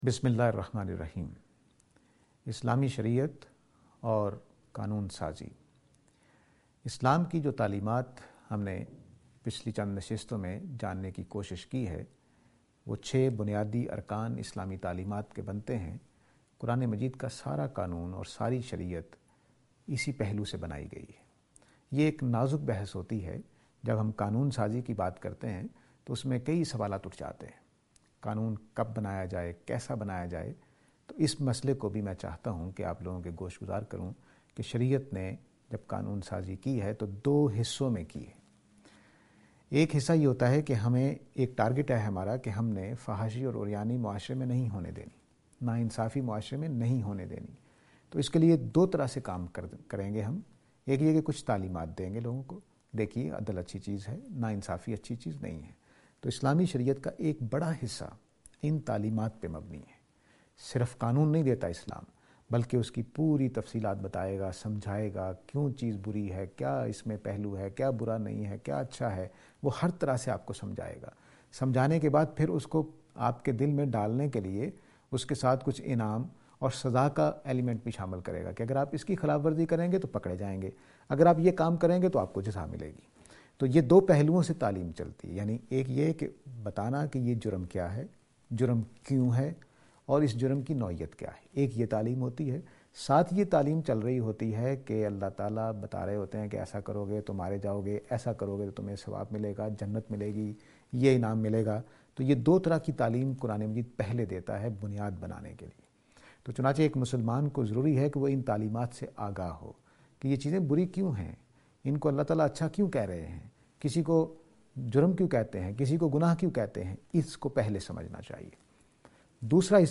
This lecture is and attempt to answer the question "Islamic Teachings and Legislation".